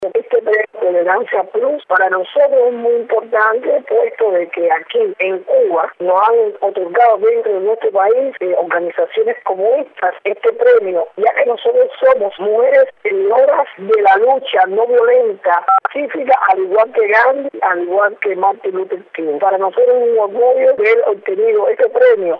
Declaraciones de Berta Soler, líder de las Damas de Blanco